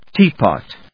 音節téa・pòt 発音記号・読み方
/ˈtiˌpɑt(米国英語), ˈti:ˌpɑ:t(英国英語)/